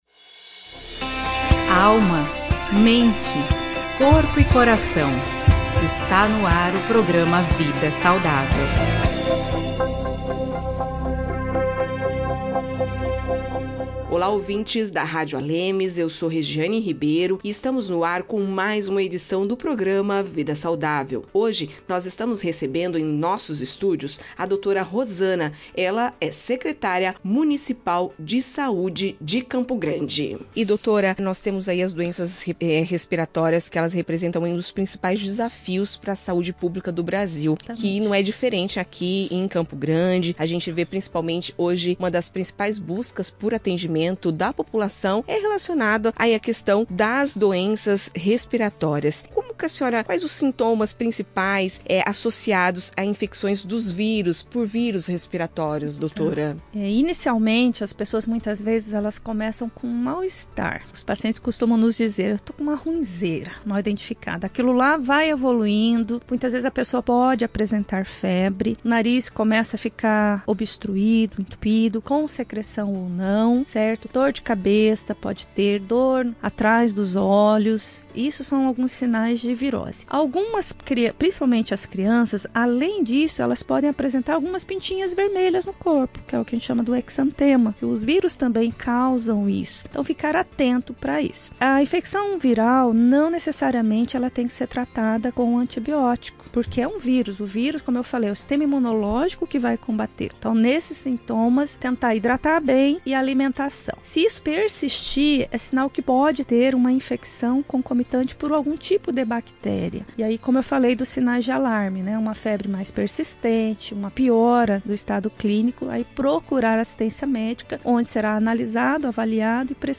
As doenças respiratórias estão entre os principais desafios da saúde pública no Brasil e no mundo, afetando milhões de pessoas todos os anos. Para falar sobre o tema, o Programa Vida Saudável, da Rádio ALEMS, recebe a médica Rosana Leite de Melo, secretária municipal de Saúde de Campo Grande. Na entrevista, ela aborda os impactos dessas enfermidades e as ações de prevenção e cuidado adotadas pelo poder público.